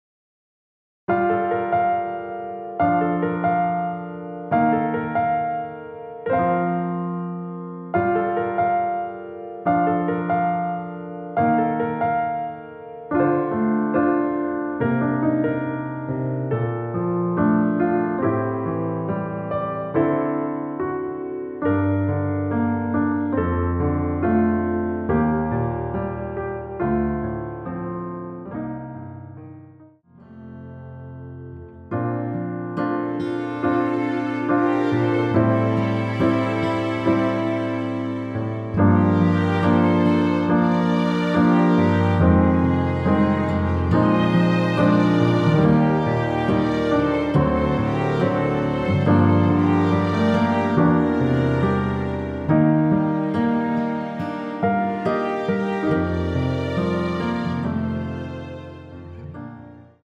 원키에서(-2)내린 MR입니다.
Bb
앞부분30초, 뒷부분30초씩 편집해서 올려 드리고 있습니다.